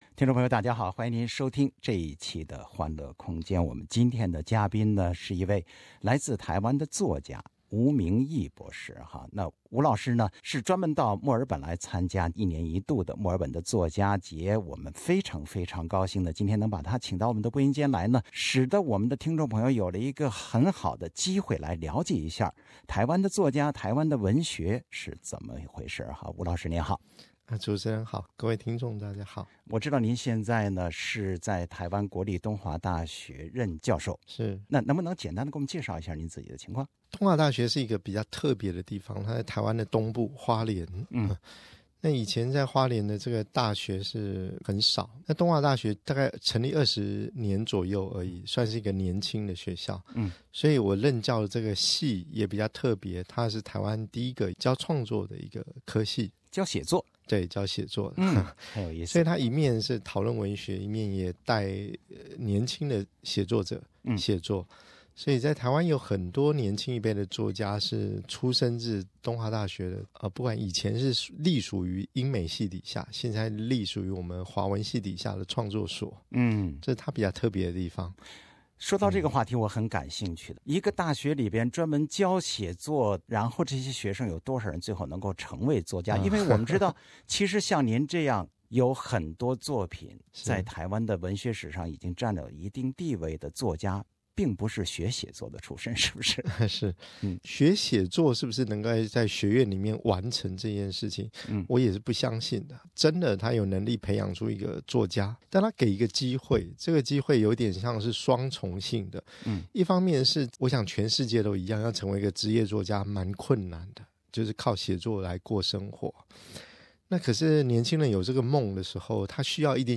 他来墨尔本参加作家节作客欢乐空间，说自己是在台北一个像贫民窟的地方出生长大，那里的生活经历为他的创作提供了丰富的资源。听吴明益教授说台湾文学，说自己的故事。